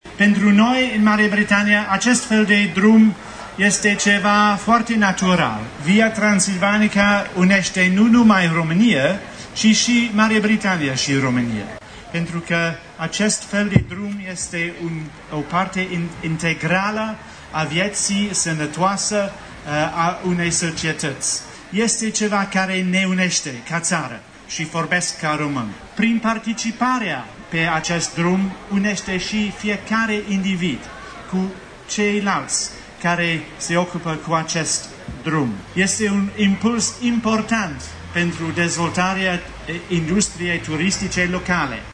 La zidirea bornei kilometrice a contribuit și ambasadorul Marii Britanii în România, Andrew James Noble, prezent ieri alături de alți oficiali, la Sovata: